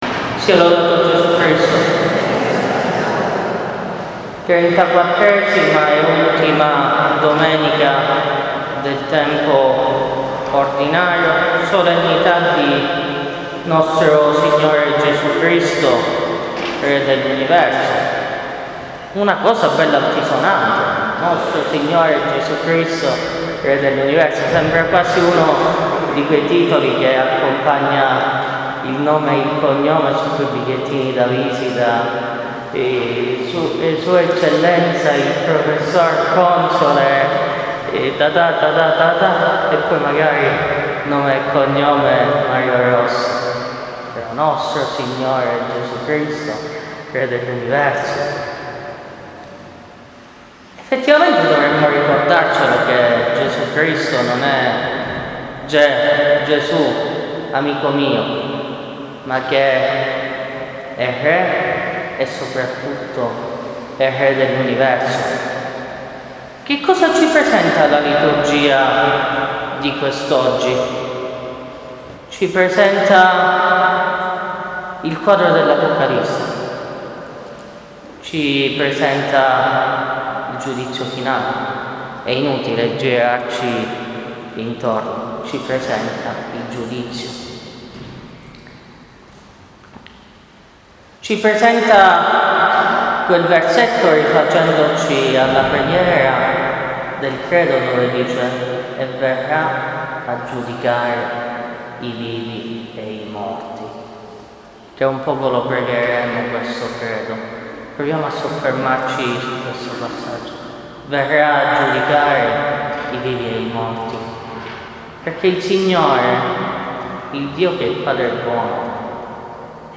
Omelia della Solennità di Cristo Re
omelia-Cristo-RE.mp3